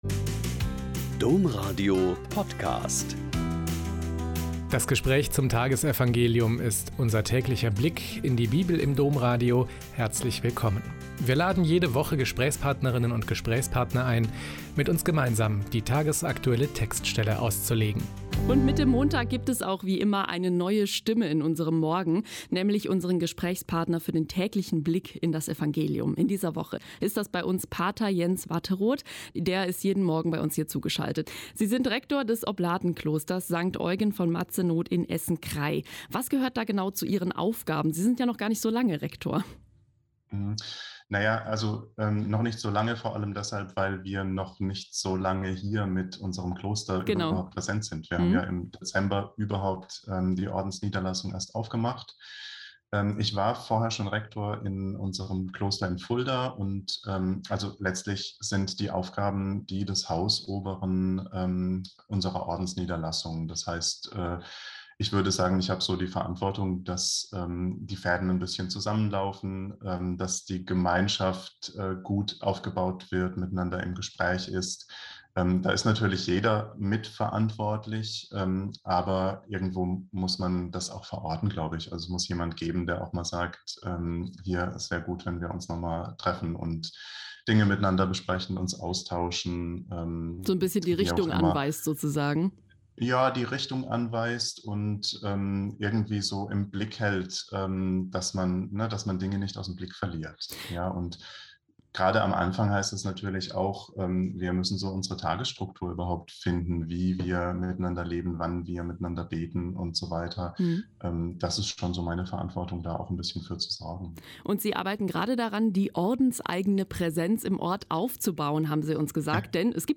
Lk 6,36-38 - Gespräch